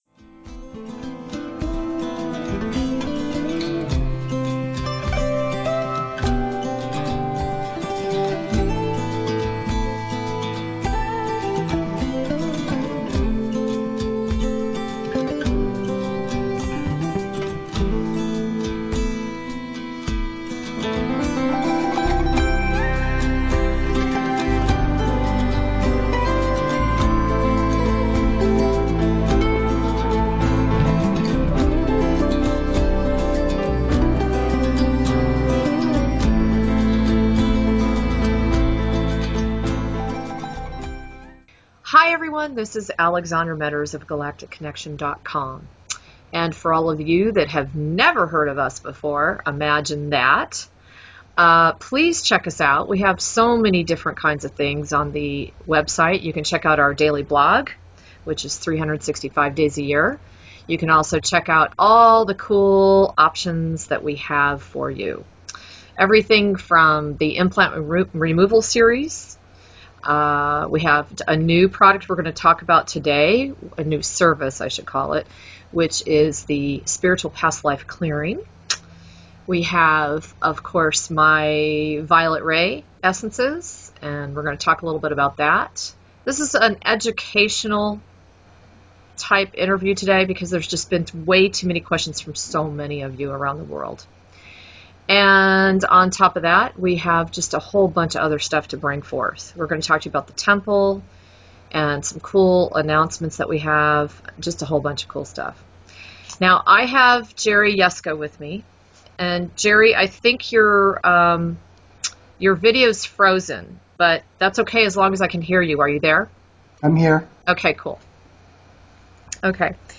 Finally an interview that many of you have been asking for!